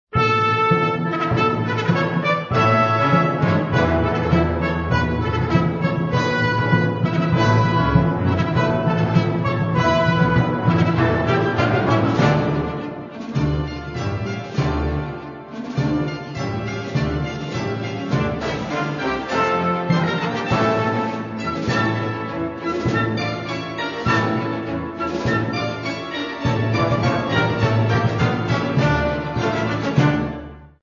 Народная